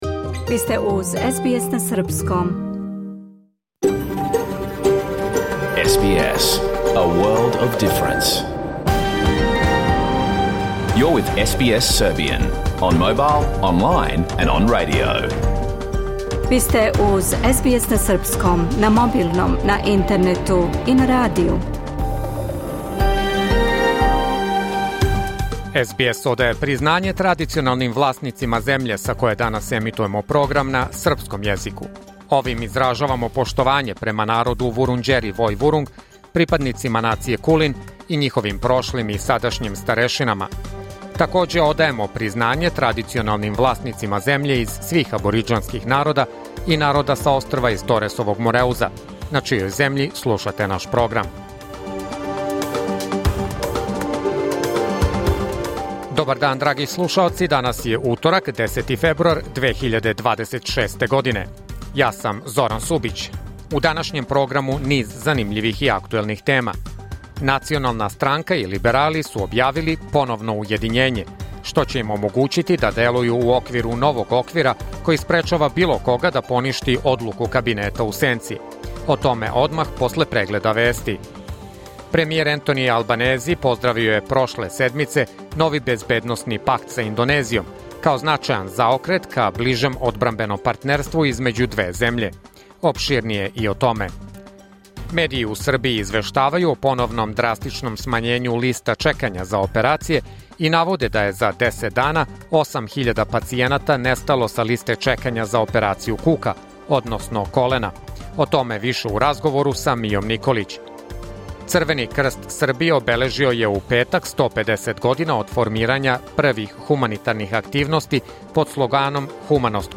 Програм емитован уживо 10. фебруара 2026. године